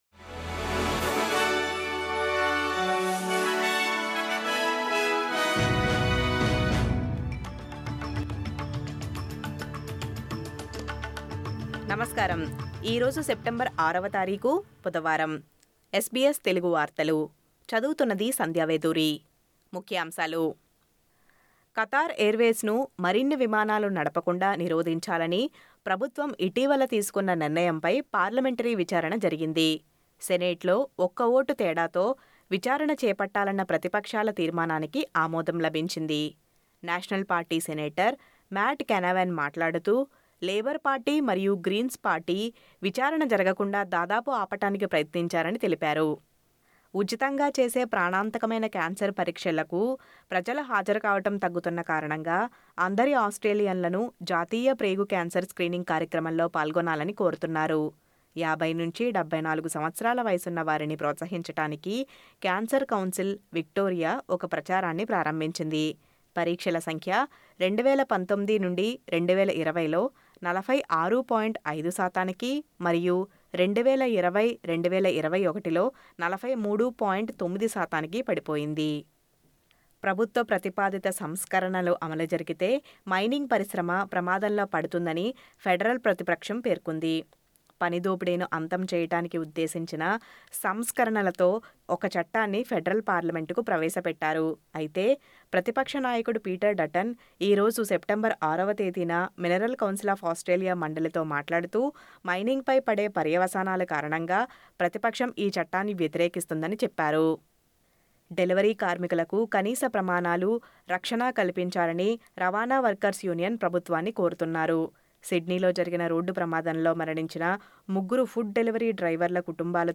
SBS Telugu వార్తలు